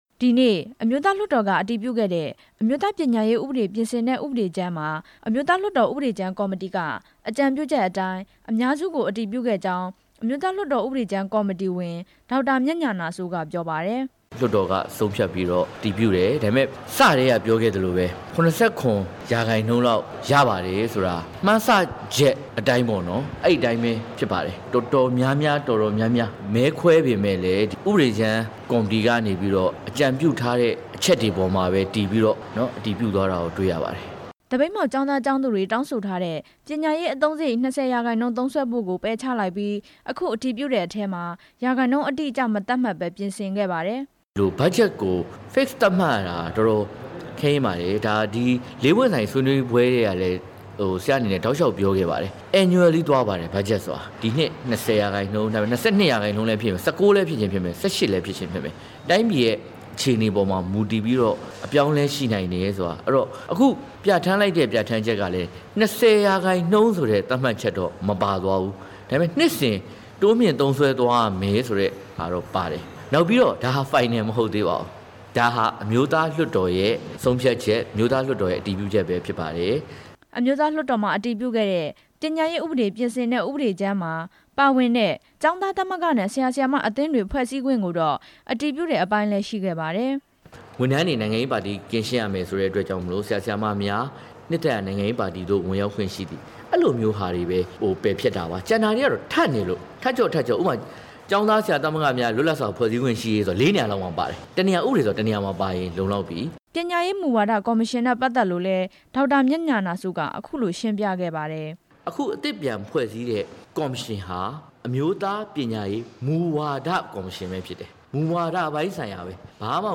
အဲ့ဒီပြင်ဆင်အတည်ပြုခဲ့တဲ့ ဥပဒေကြမ်းမှာပါဝင်တဲ့ အချက်တွေကို အမျိုးသားလွှတ်တော်ကိုယ်စားလှယ် ဒေါက်တာမြတ်ဉာဏစိုးက သတင်းထောက်တွေကို ရှင်းပြခဲ့ပါတယ်။